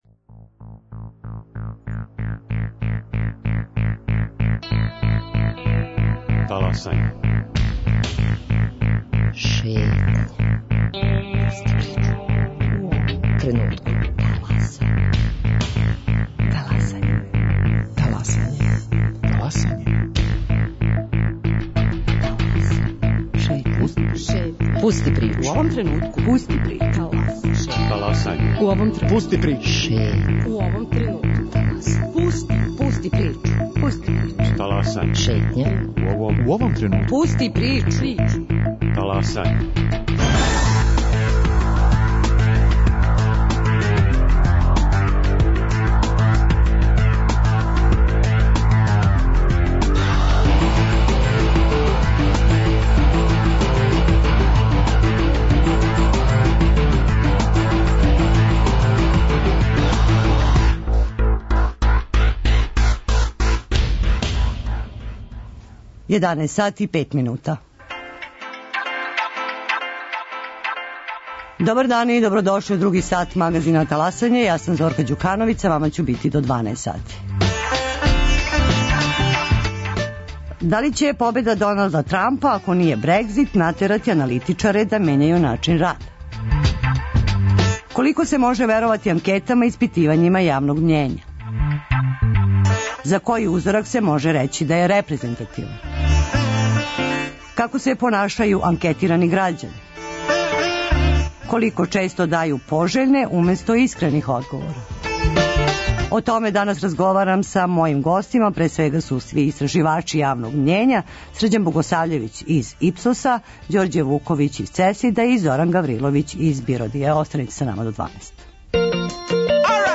Гости истраживачи јавног мњења